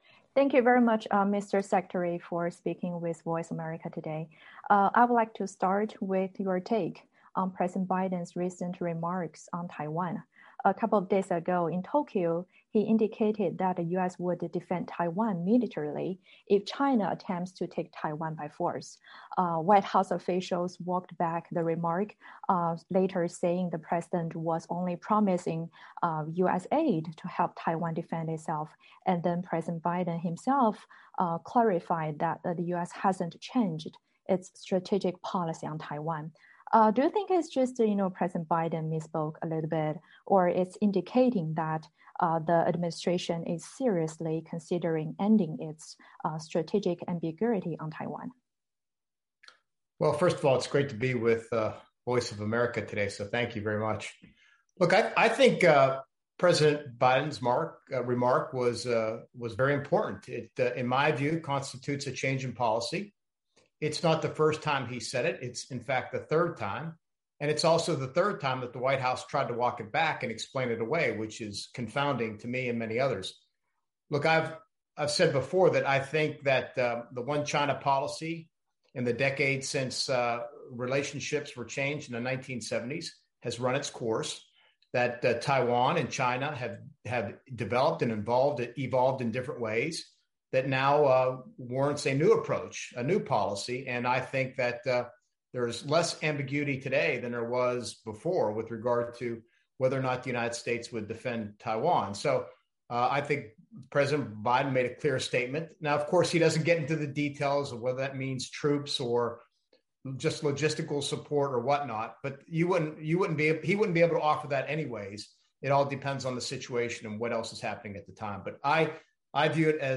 VOA专访: 专访美国前防长埃斯珀：美国需要重新审视“一个中国”政策
前美国国防部长埃斯珀在接受美国之音专访时说，拜登在日本所说的“美国会军事保卫台湾”并非口误，而是预示着美国政府在台湾问题上的“战略模糊”政策或将终结。在专访中，前防长埃斯珀还谈到如果美国改变对台政策是否会引发美中军事冲突、在可能的军事冲突威胁下美台应如何应对等问题。